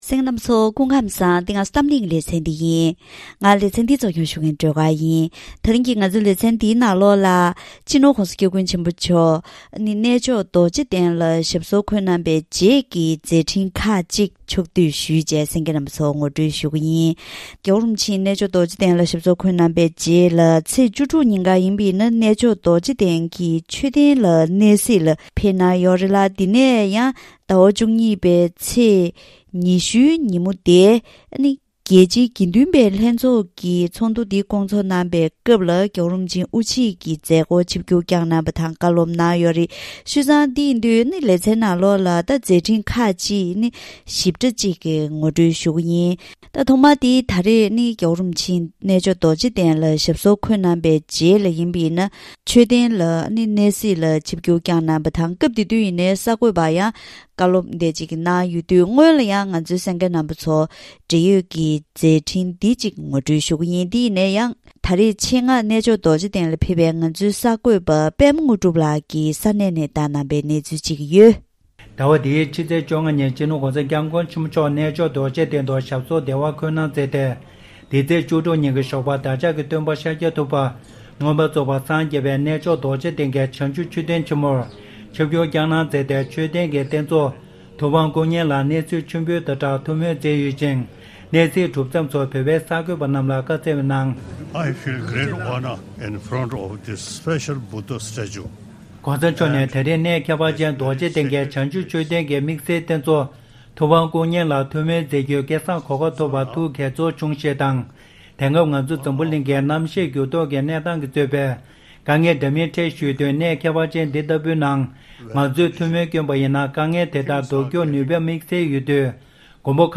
ད་རིང་གི་གཏམ་གླེང་ལེ་ཚན་ནང་།